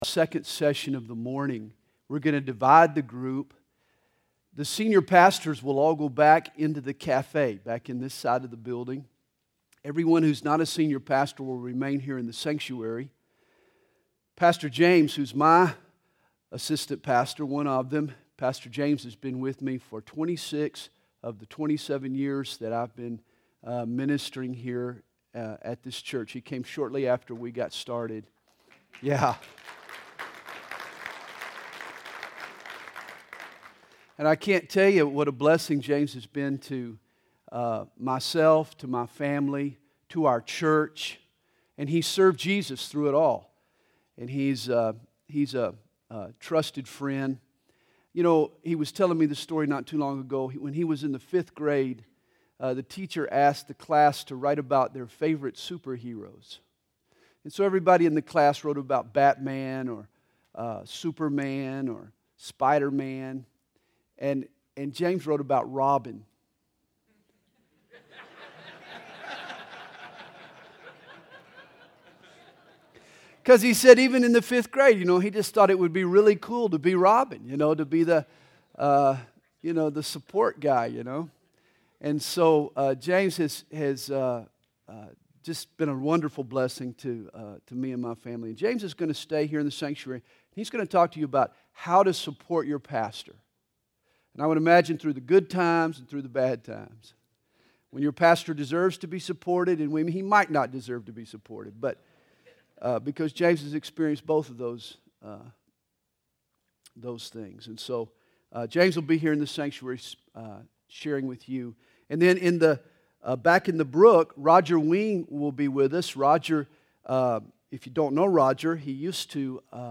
2008 Home » Sermons » Session 4 Share Facebook Twitter LinkedIn Email Topics